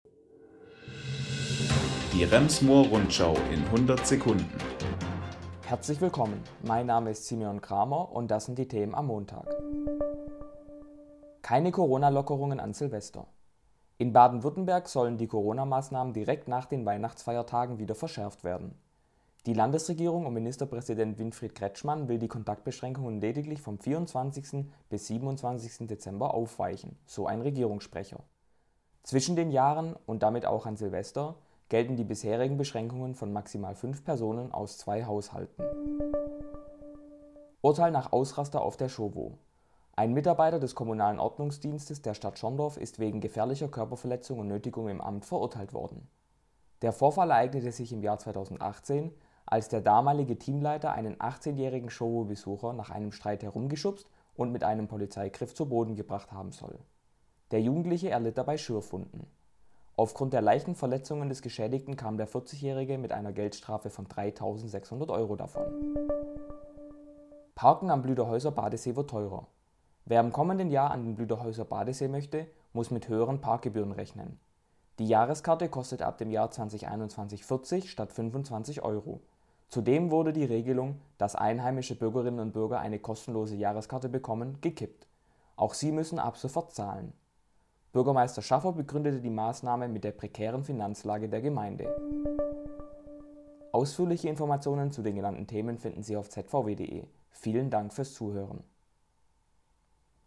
Die wichtigsten Nachrichten des Tages